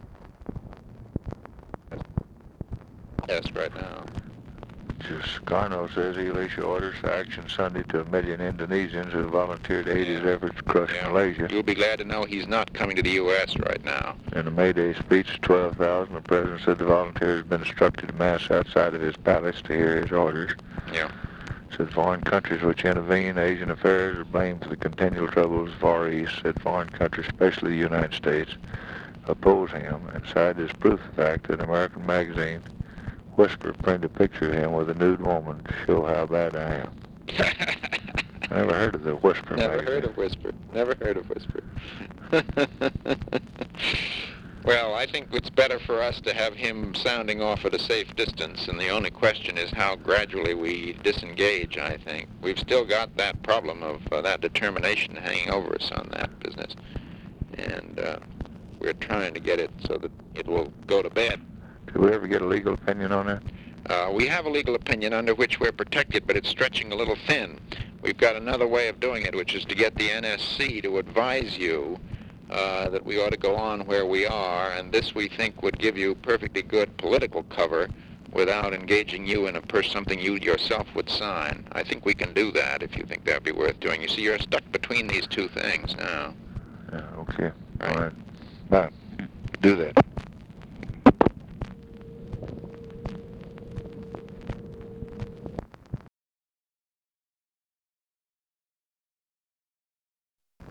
Conversation with MCGEORGE BUNDY, May 1, 1964
Secret White House Tapes